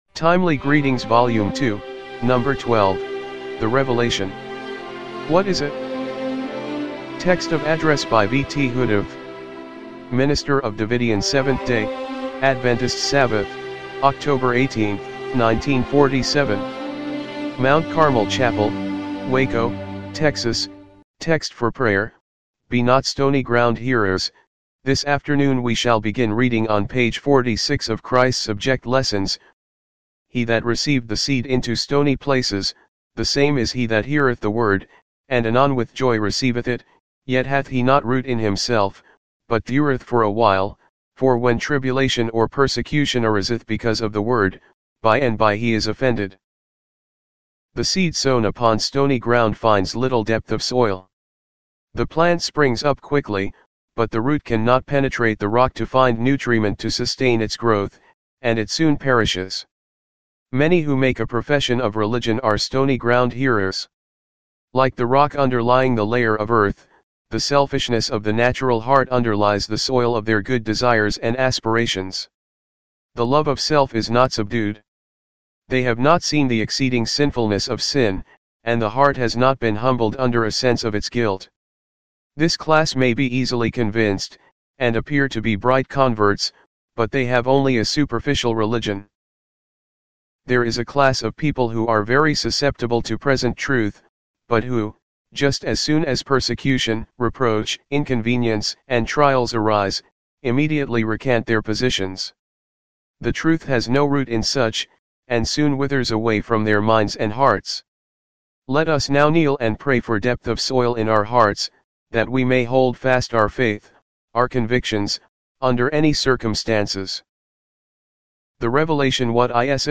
timely-greetings-volume-2-no.-12-mono-mp3.mp3